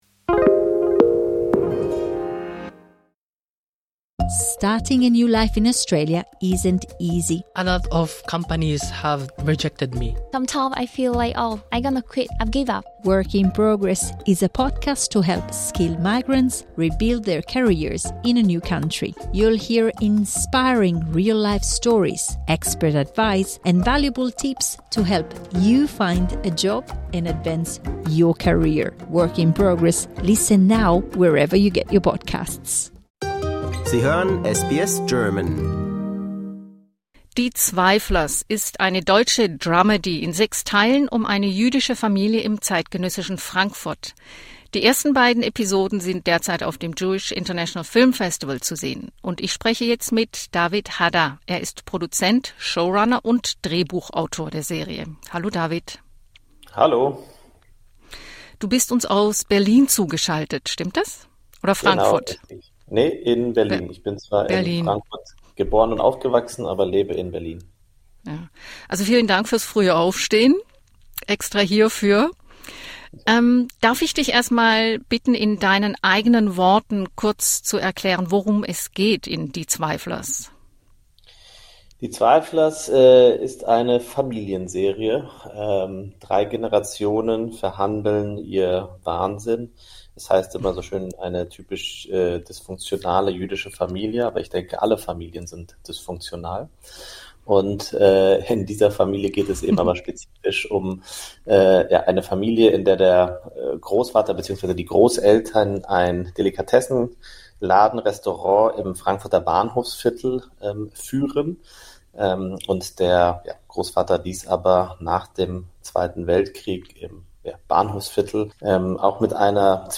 Entdecken Sie mehr Geschichten, Interviews und Nachrichten von SBS German in unserer Podcast-Sammlung.